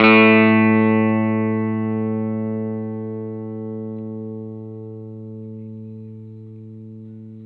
R12 NOTE  A.wav